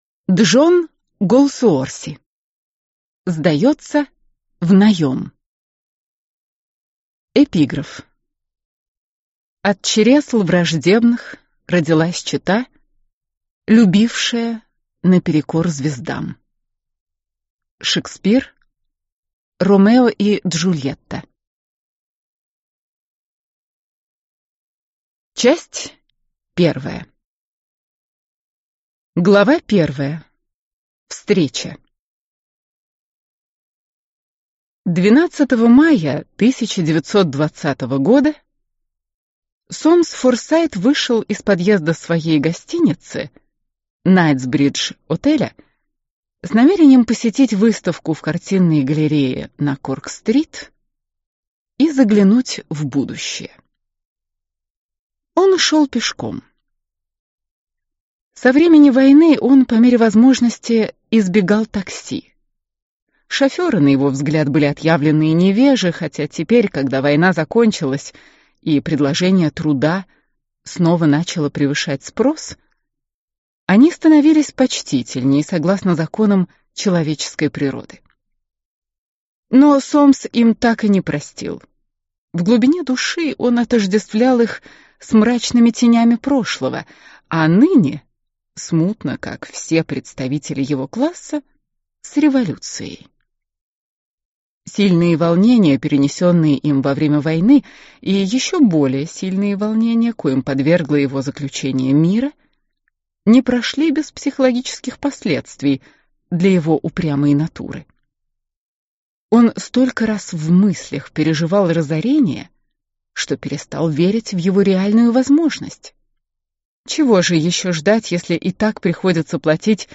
Аудиокнига Сдается в наем | Библиотека аудиокниг